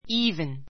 even 小 A2 íːvn イ ー ヴ ン 副詞 ❶ even ～ で ～でさえ , ～でも even now even now 今でさえ, 今でも even in March even in March 3月になっても It is very easy.